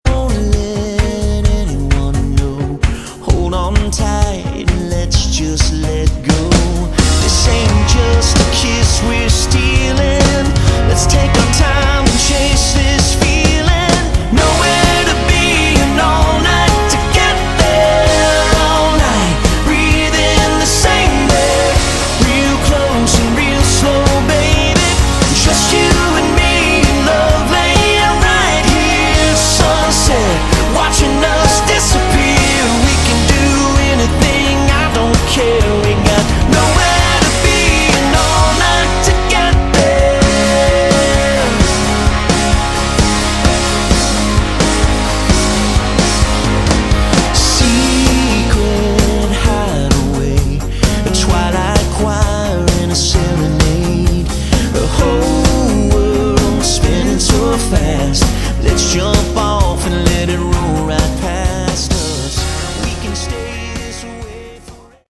Category: AOR / Melodic Rock
vocals, keyboards, guitars